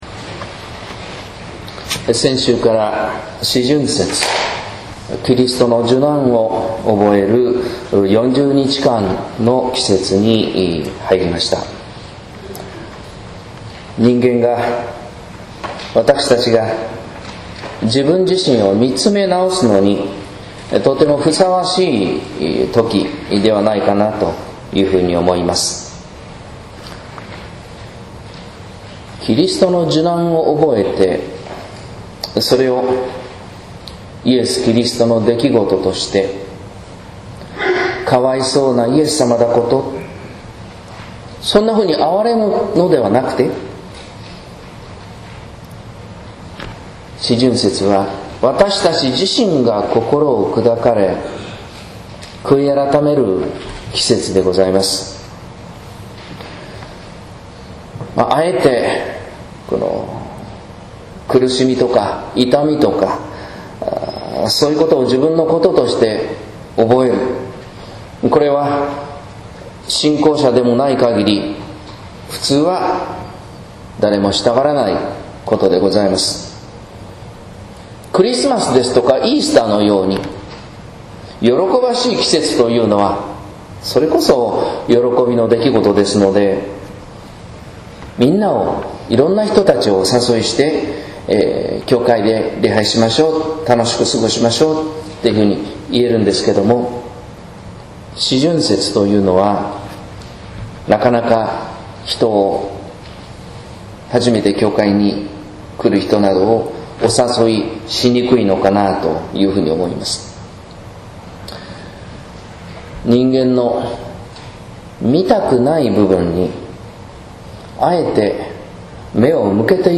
説教「仕えるライフスタイル」（音声版） | 日本福音ルーテル市ヶ谷教会